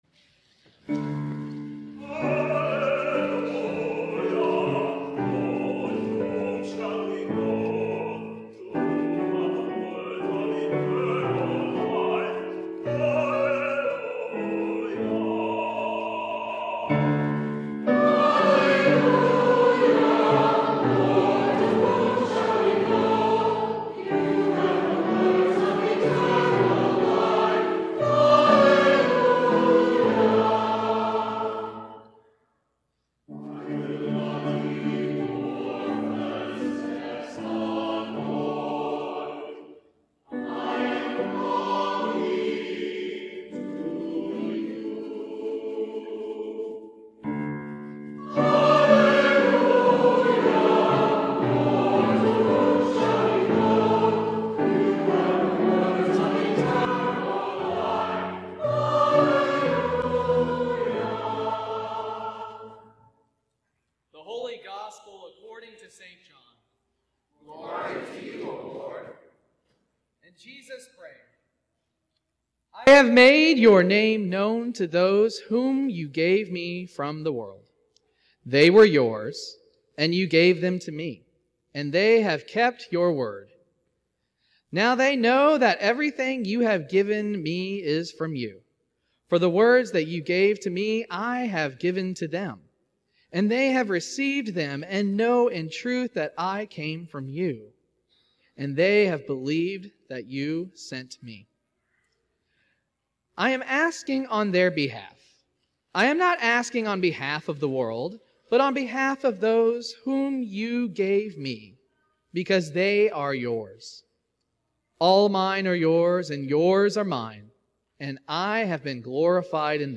Sermon from the Seventh Sunday of Easter